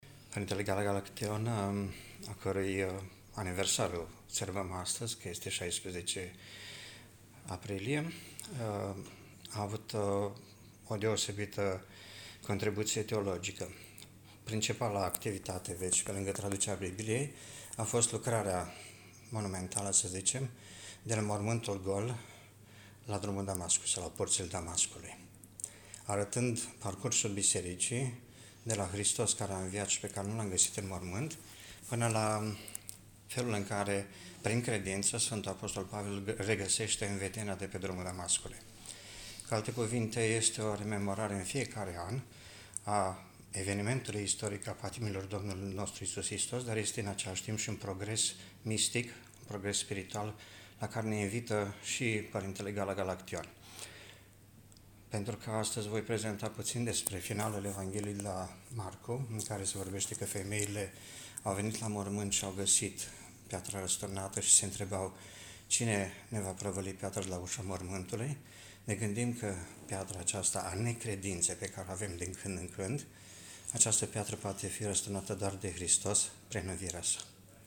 Universitatea „Alexandru Ioan Cuza” din Iași (UAIC) a organizat miercuri, 16 aprilie 2025, de la ora 12.00, în Mansarda Muzeului UAIC, conferința „Gala Galaction – profesor la Universitatea din Iași (1926-1940)”.